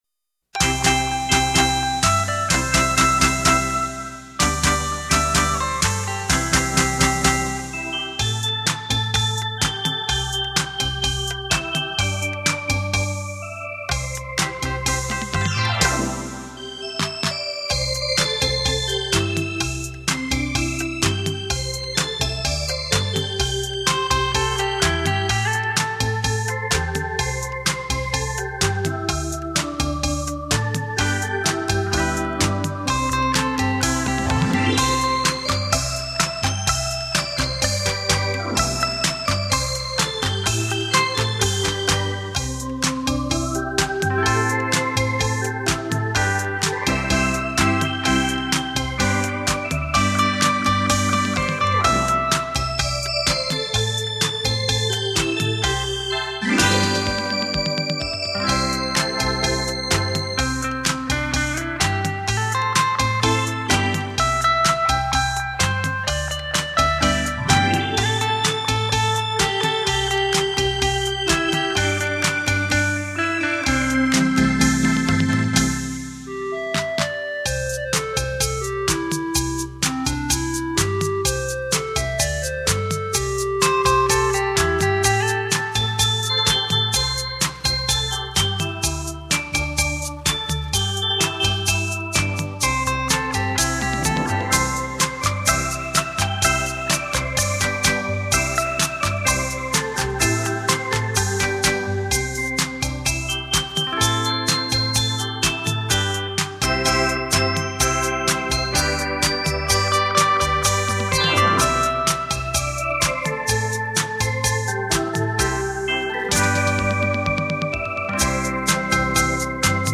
悦耳动容的丝弦本色
唯美而浪漫的曲调
演绎流行的现代音乐